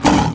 spawners_mobs_uruk_hai_attack.1.ogg